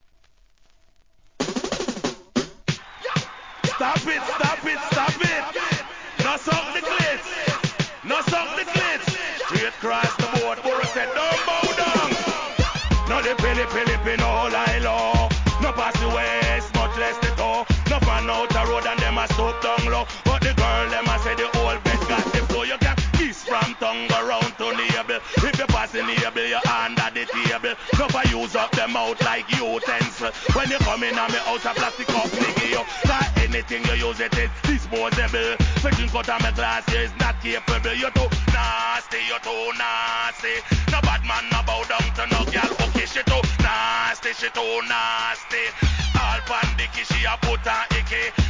REGGAE
DUCK調の人気RHYTHM!!